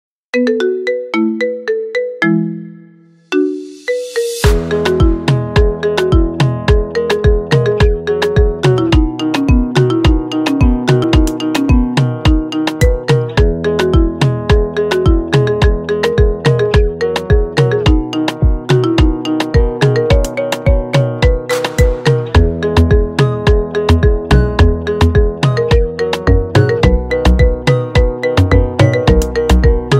متقنة بالة الماريمبا بدون غناء